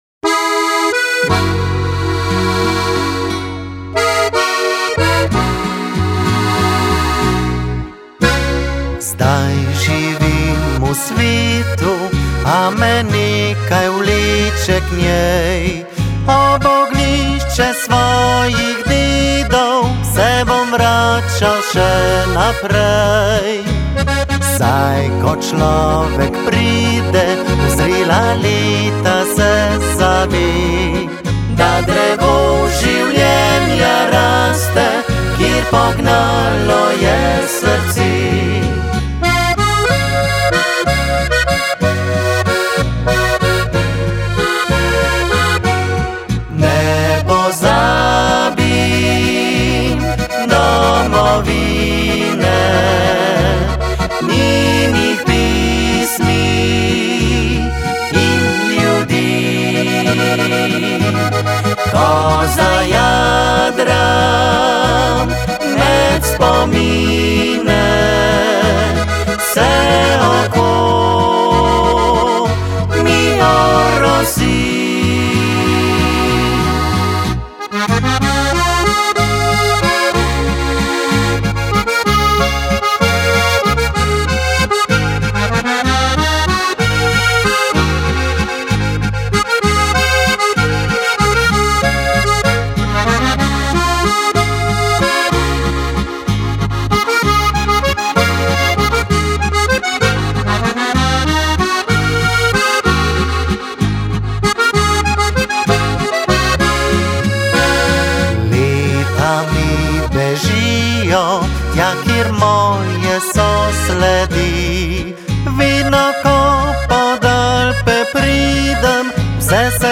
petčlanska zasedba – trio s pevko in pevcem
• Narodno zabavna glasba